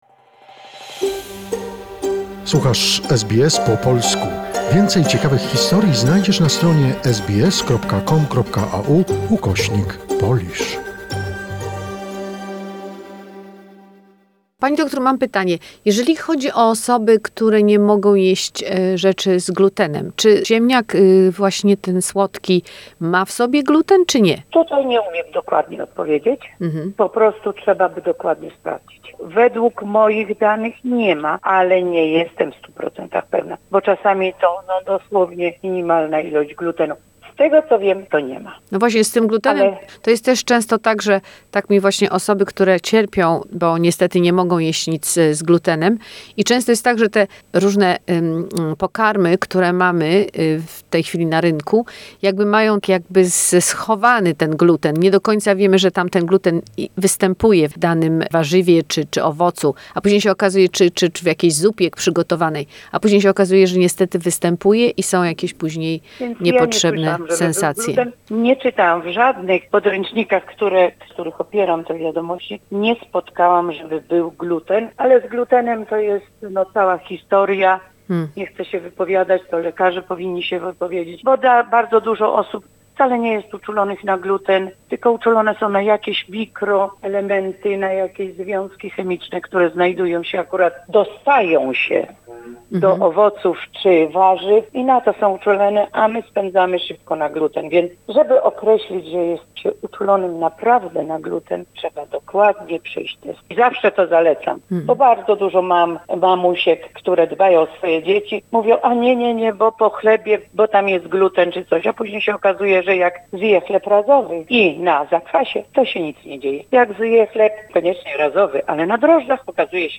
The conversation